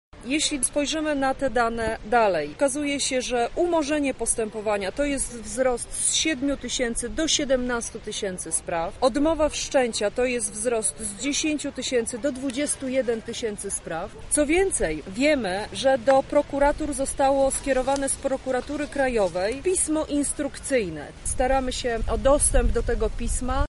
-mówi Joanna Mucha z Platformy Obywatelskiej.